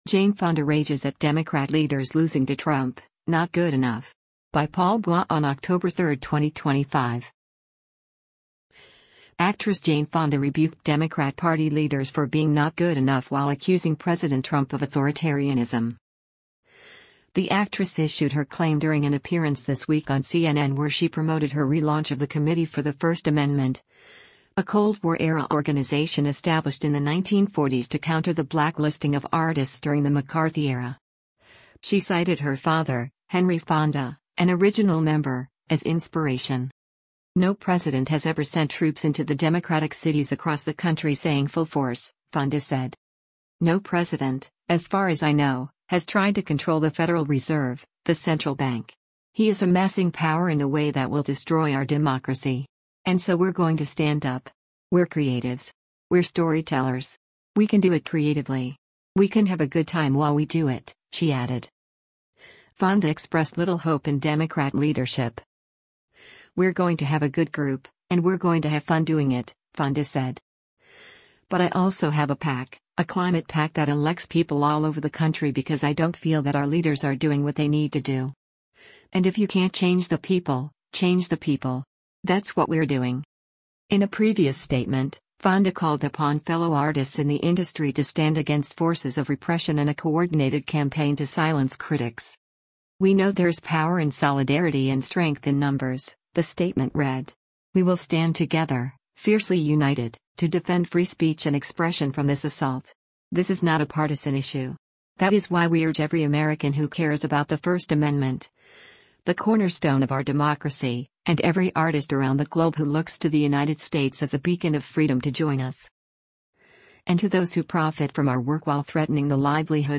The actress issued her claim during an appearance this week on CNN where she promoted her relaunch of the Committee for the First Amendment – a Cold War era organization established in the 1940s to counter the blacklisting of artists during the McCarthy era.